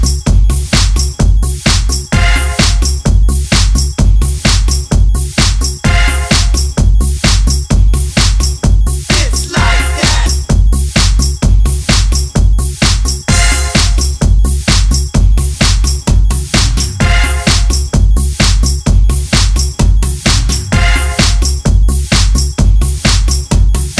Rapper Duet R'n'B / Hip Hop 4:09 Buy £1.50